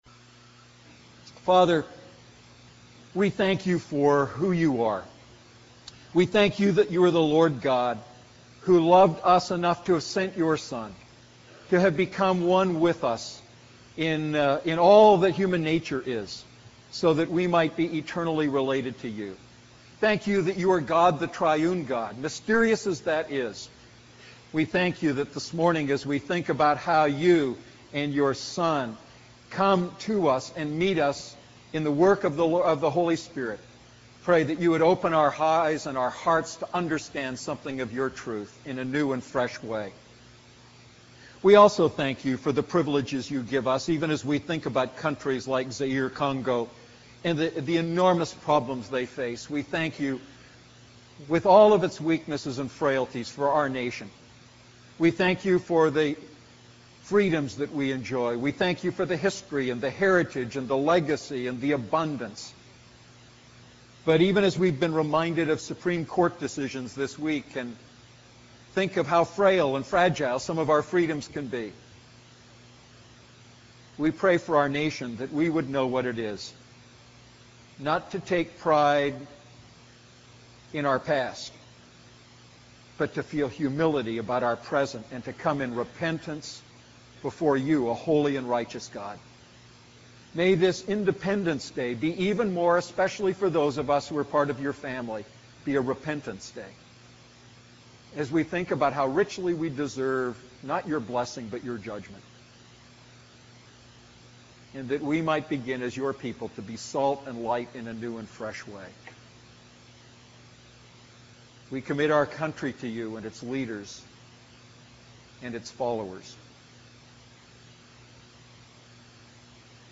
A message from the series "Holy Spirit."